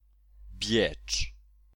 Biecz (Polish pronunciation: ['bʲɛt͡ʂ]
Pl-Biecz.ogg.mp3